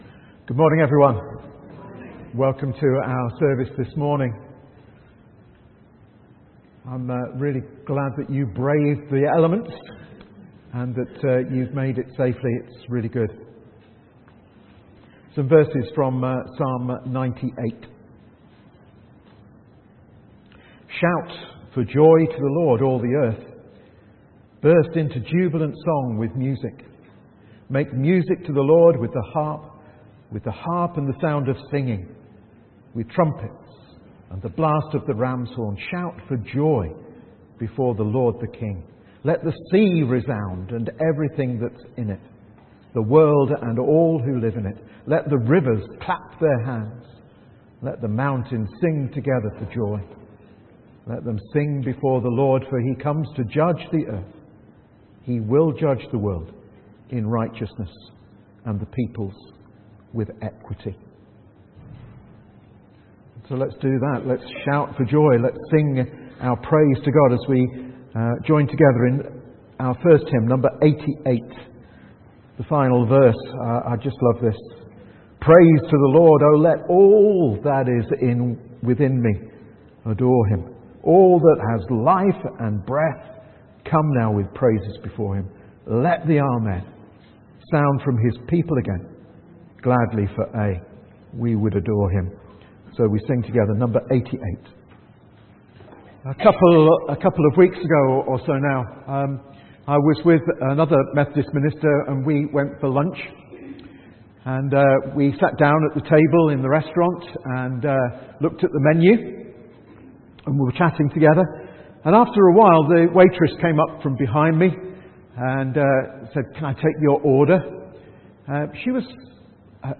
Message
From Service: "9.00am Service"